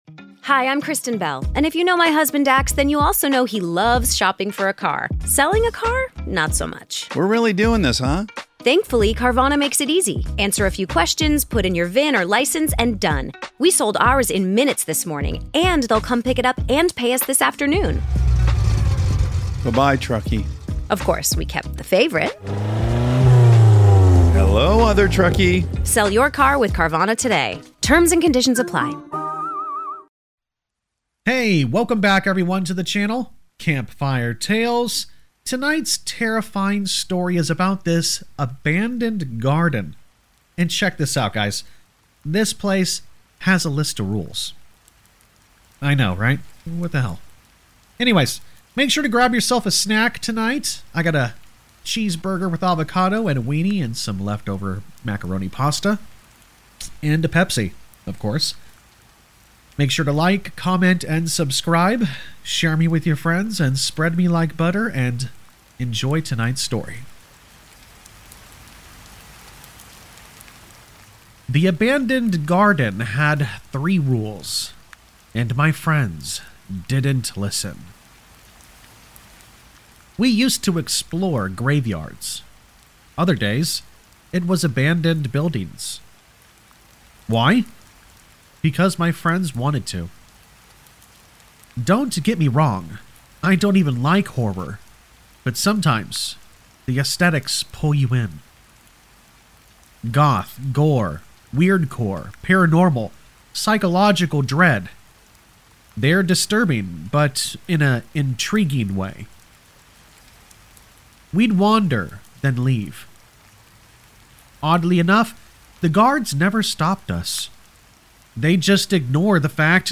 If you love Scary Stories that build dread and suspense, you won’t want to miss tonight’s haunting narrative.
All Stories are read with full permission from the authors: